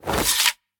retract1.ogg